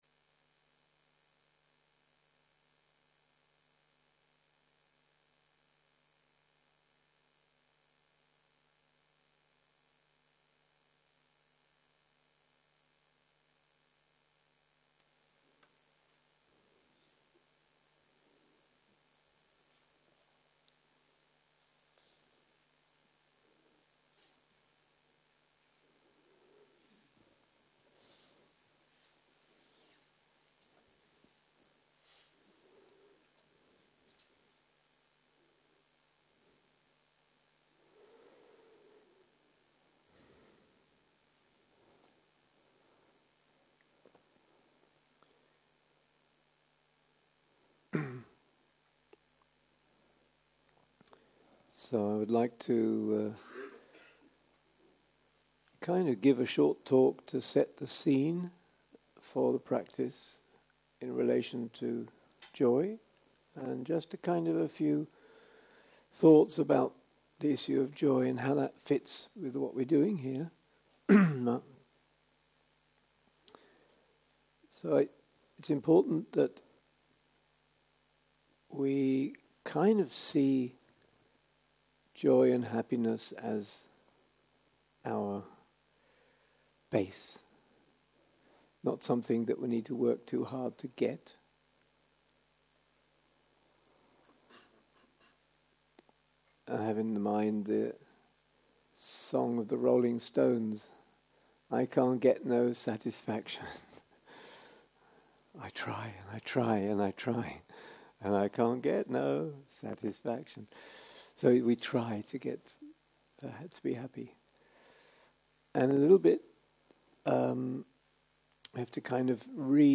יום 1 - ערב - שיחת דהרמה - Three Ways to Recognize Our Deep Happiness - הקלטה 1 0:00 0:00 סוג ההקלטה: Dharma type: Dharma Talks שפת ההקלטה: Dharma talk language: English